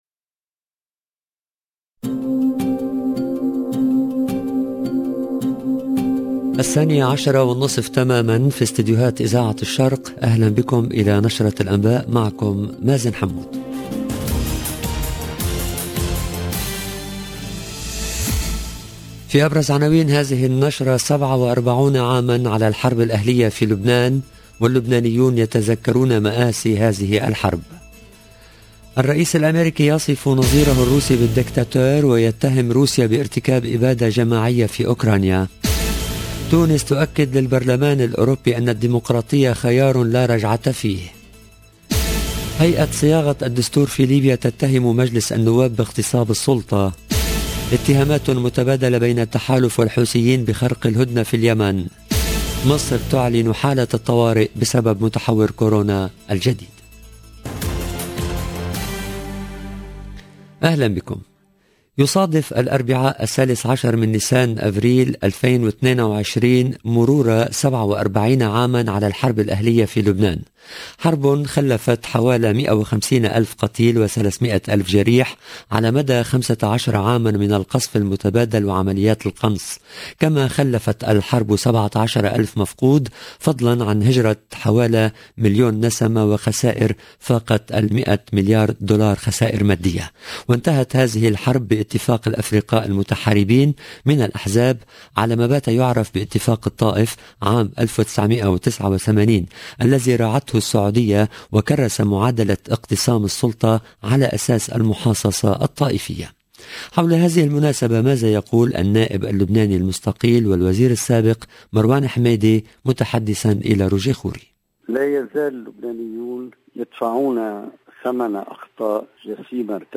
LE JOURNAL EN LANGUE ARABE DE MIDI 30 DU 13/04/22